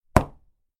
دانلود آهنگ مهر زدن از افکت صوتی اشیاء
دانلود صدای مهر زدن از ساعد نیوز با لینک مستقیم و کیفیت بالا
جلوه های صوتی